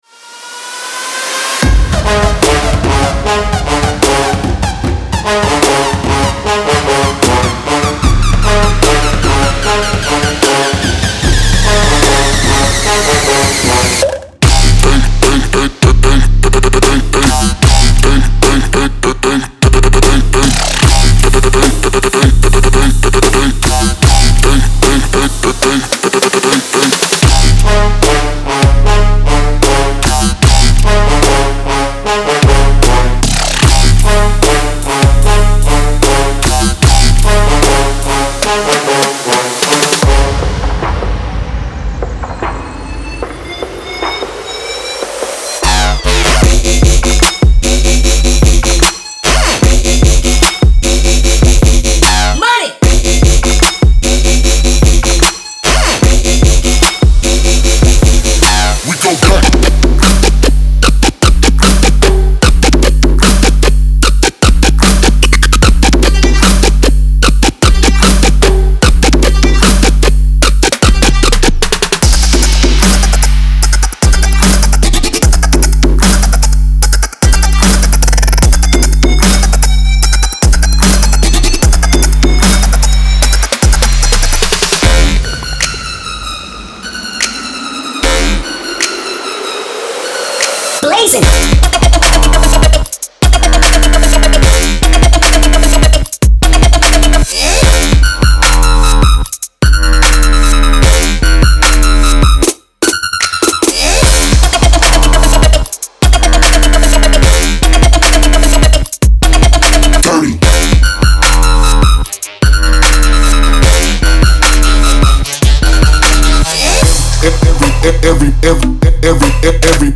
DubstepTrap
• 100 Trapstep Beats
• 100 Sub & Bass Loops
• 30 Vocal Shouts